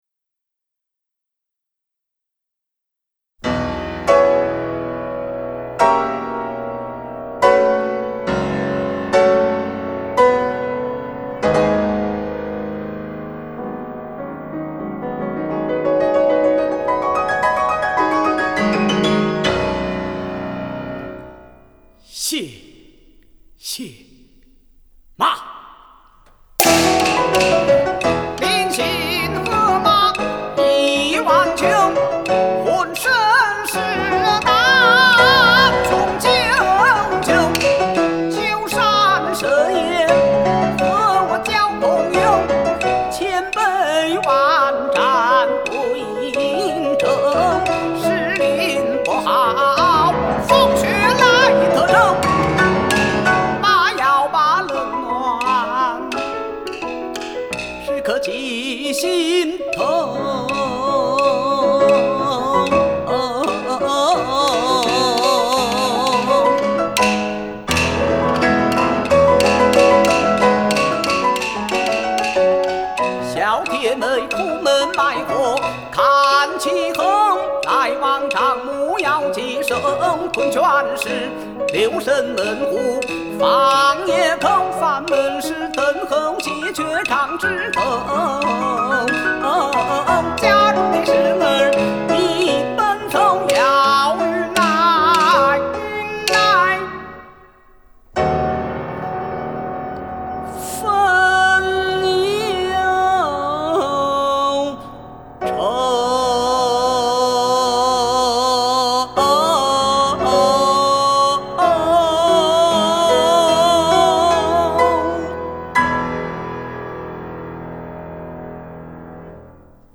发烧京剧
钢琴